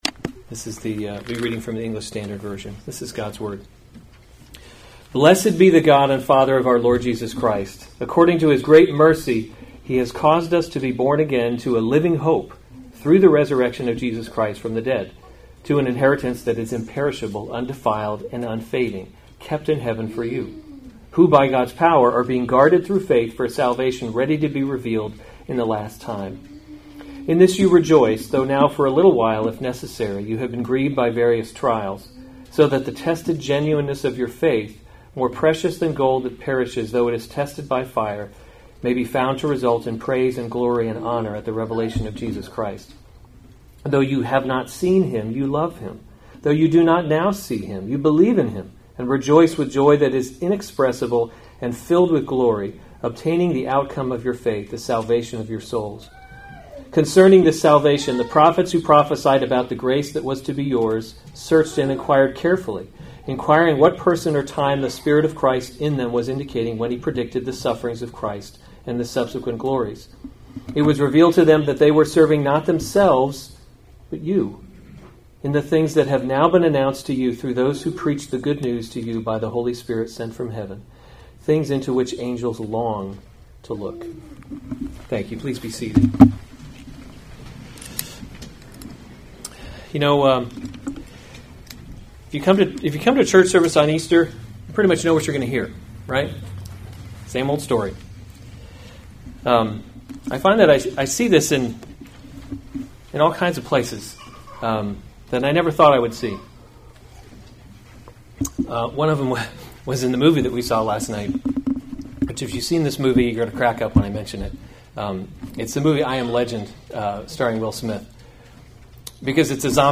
April 20, 2019 Special Services series Easter Service Save/Download this sermon 1 Peter 1:3-12 Other sermons from 1 Peter Born Again to a Living Hope 3 Blessed be the God and […]